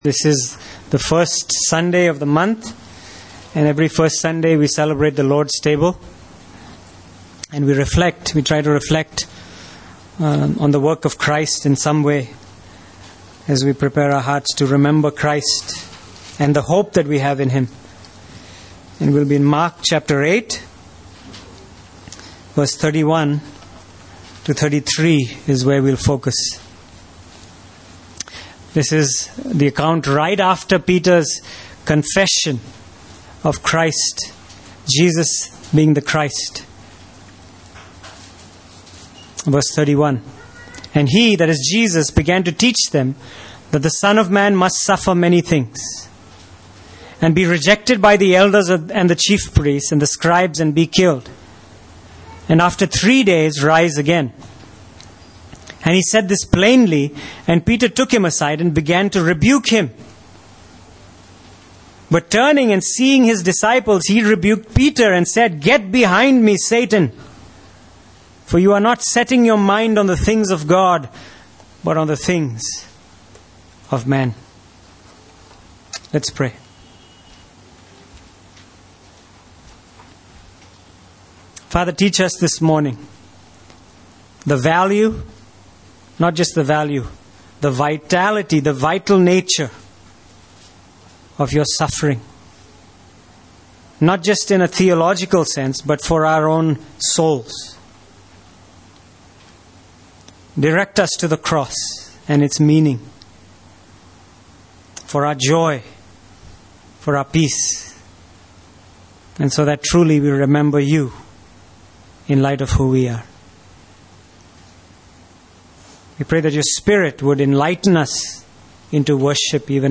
Passage: Mark 8:31-33 Service Type: Sunday Morning